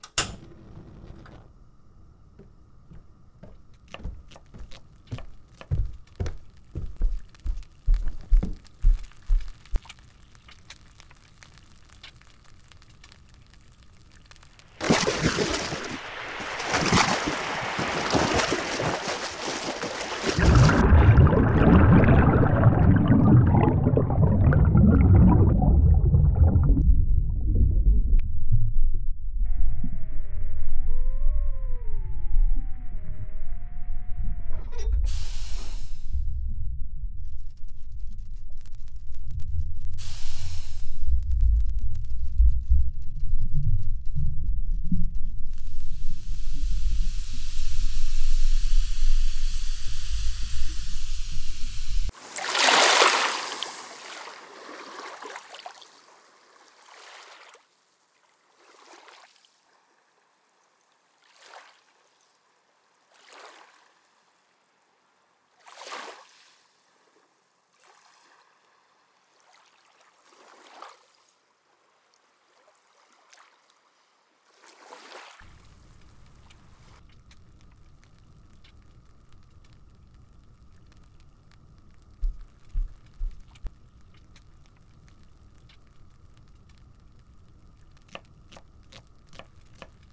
【声劇】 11°21'N,142°12'E